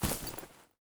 255081e1ee Divergent / mods / Soundscape Overhaul / gamedata / sounds / material / actor / step / grass3.ogg 28 KiB (Stored with Git LFS) Raw History
grass3.ogg